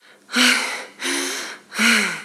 Jadeo
interjección
Sonidos: Acciones humanas
Sonidos: Voz humana